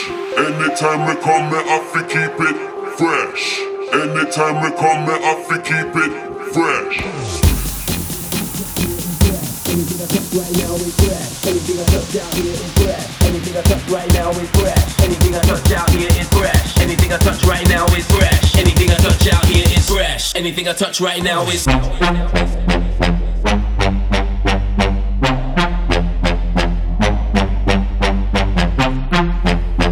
• Garage